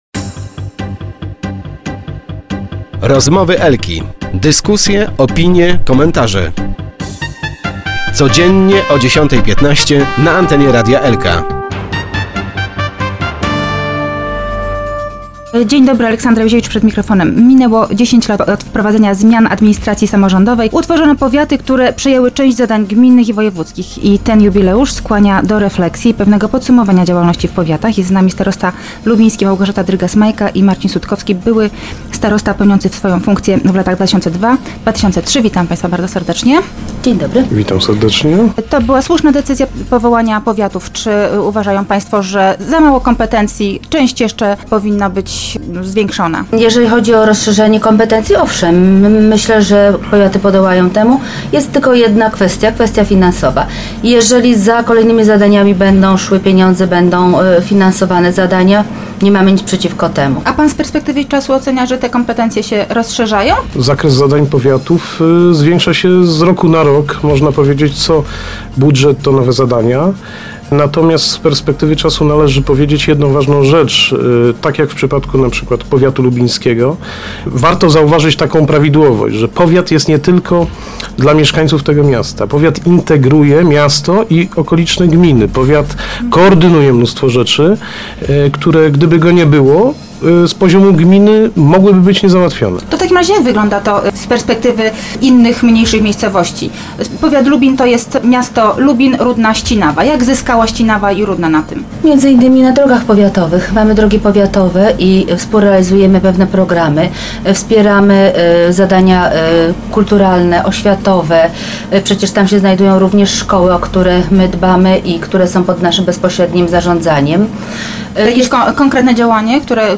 Refleksje i podsumowanie pracy powiatu lubińskiego w 10 rocznicę reformy administracji samorządowej w rozmowie ze starostą lubińskim Małgorzatą Drygas – Majką i byłym starostą Marcinem Sutkowskim w  Rozmowach Elki.